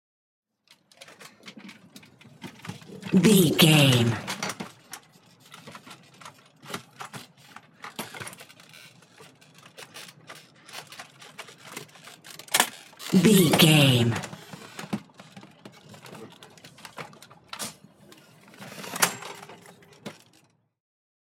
Ambulance Stretcher Litter In Out
Sound Effects
chaotic
emergency